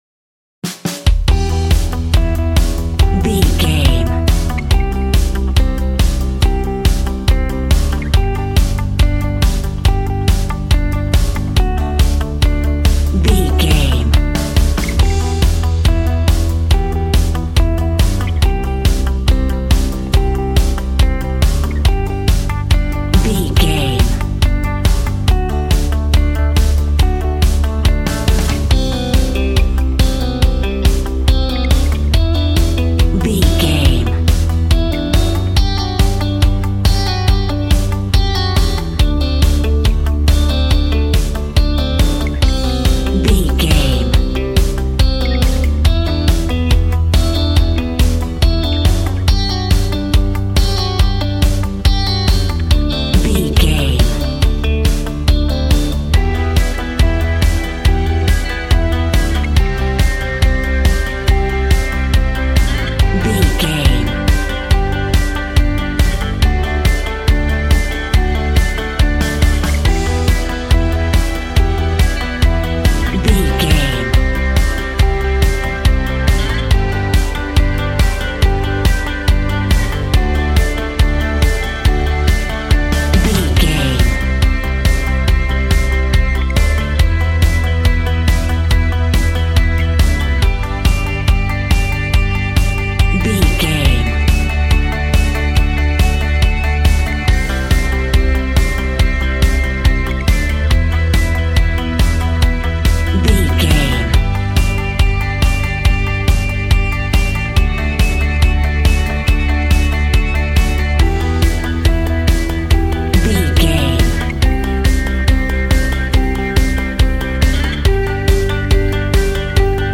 Ionian/Major
groovy
powerful
organ
drums
bass guitar
electric guitar
piano